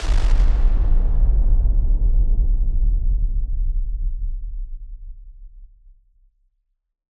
BF_SynthBomb_D-03.wav